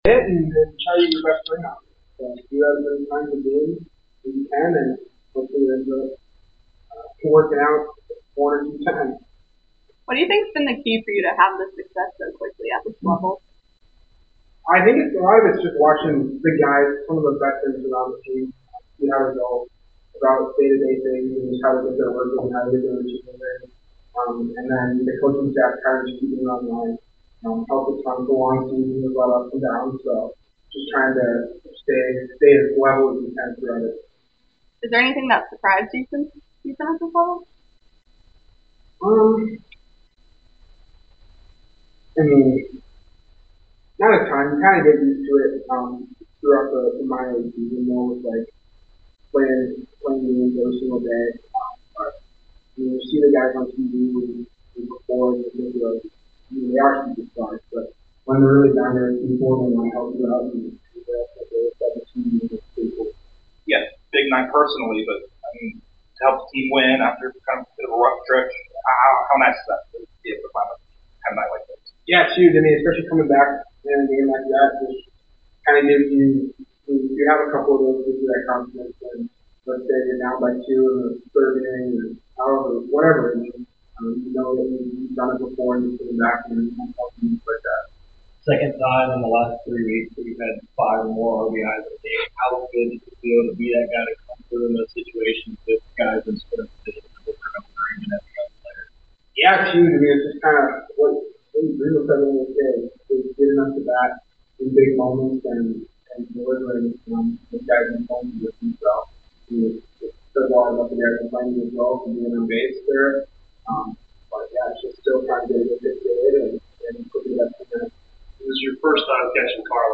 Atlanta Braves Catcher Drake Baldwin Postgame Interview after defeating the Miami Marlins at Truist Park.